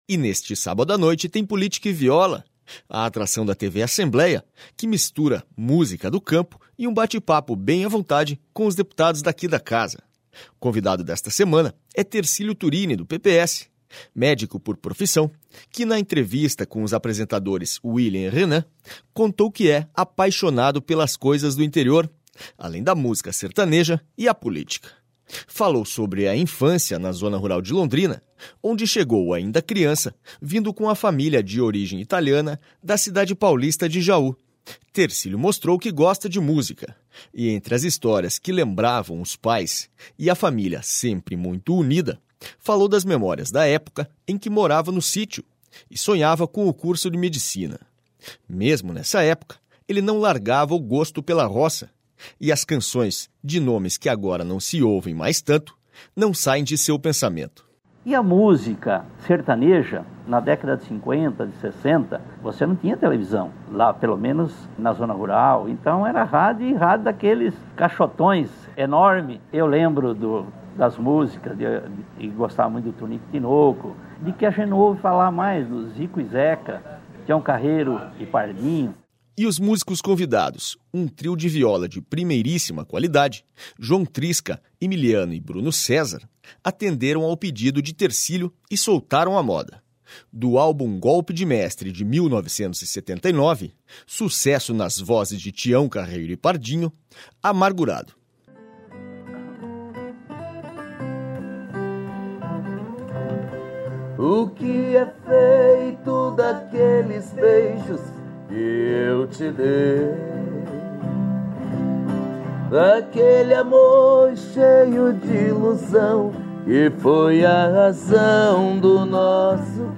E neste sábado à noite tem Política e Viola, a atração da TV Assembleia que mistura música do campo e um bate papo bem à vontade com os deputados daqui da Casa.